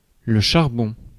Ääntäminen
France: IPA: [ʃaʁ.bɔ̃]